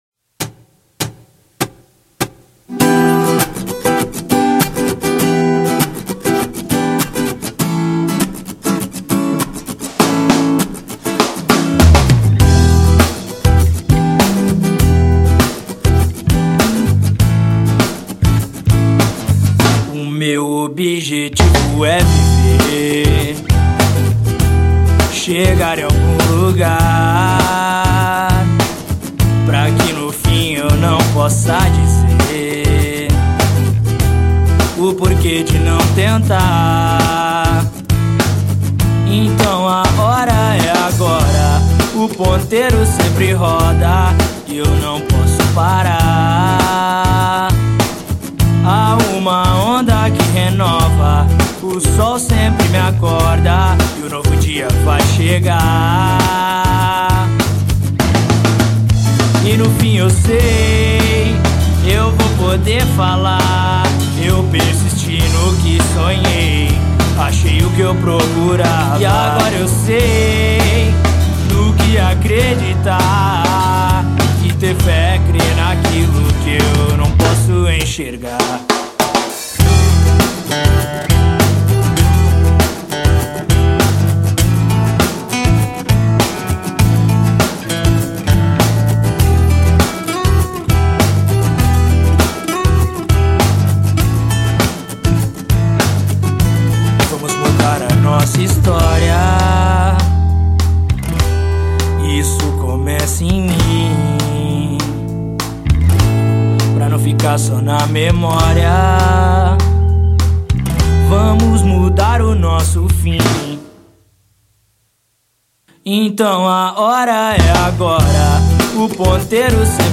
EstiloSurf Music